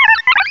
Cri de Ptiravi dans Pokémon Diamant et Perle.
Cri_0440_DP.ogg